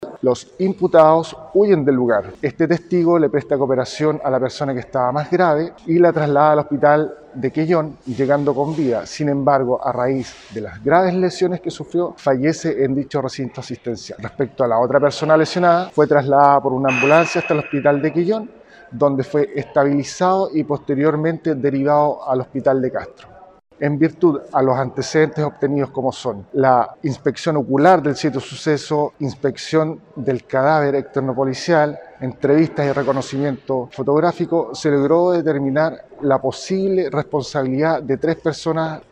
En lugar del suceso, se encontraba un testigo de los hechos el que prestó ayuda a los afectados, proporcionando además el relato a la Brigada de Homicidios, destacó el oficial de la PDI.